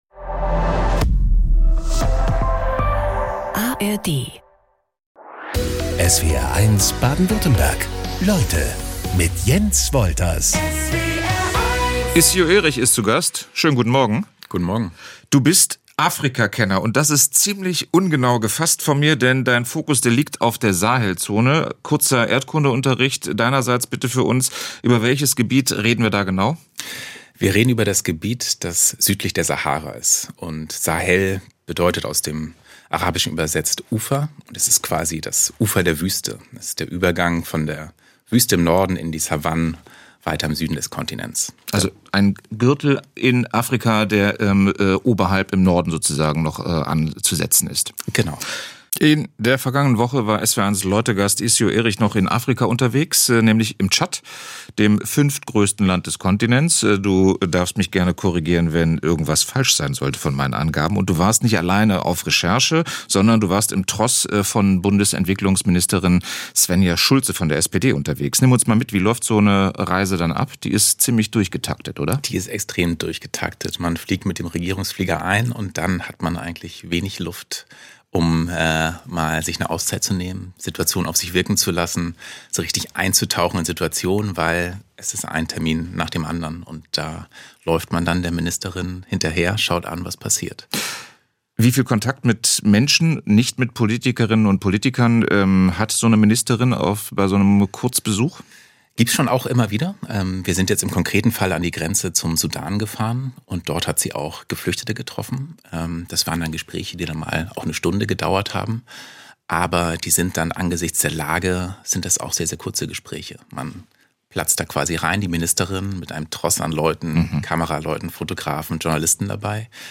Jeden Tag talken unsere SWR1 Leute-Moderator:innen in Baden-Württemberg und Rheinland-Pfalz mit interessanten und spannenden Gästen im Studio.